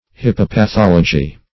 Search Result for " hippopathology" : The Collaborative International Dictionary of English v.0.48: Hippopathology \Hip`po*pa*thol`o*gy\, n. [Gr.